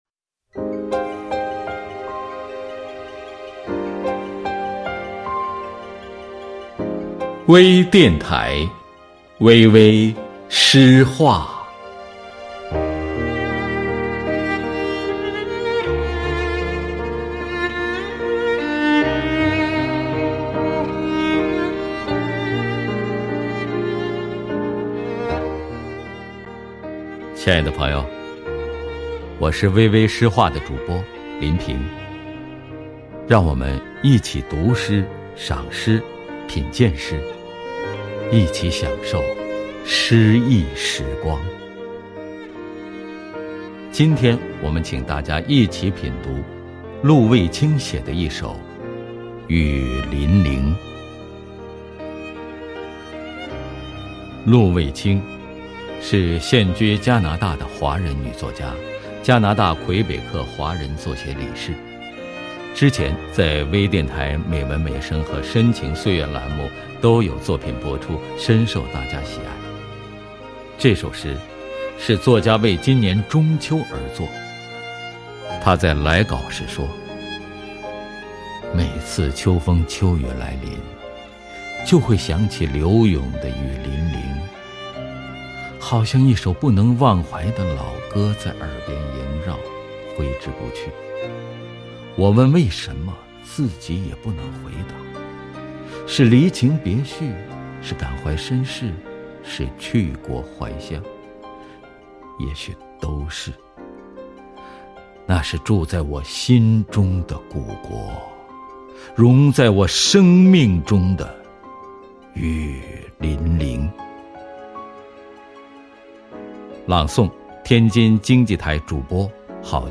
多彩美文  专业诵读
朗 诵 者